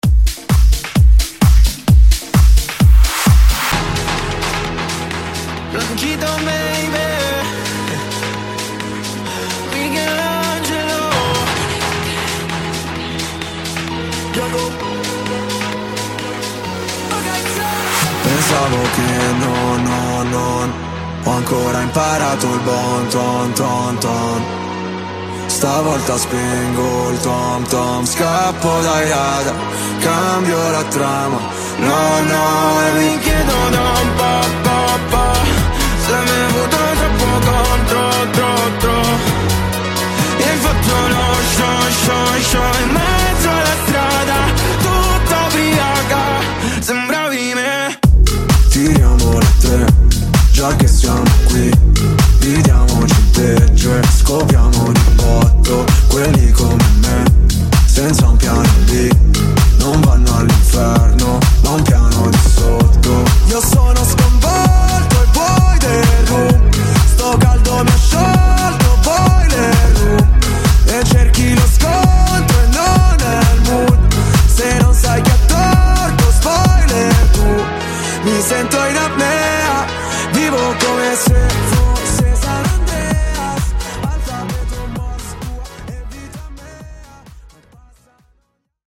Genre: GERMAN MUSIC Version: Clean BPM: 135 Time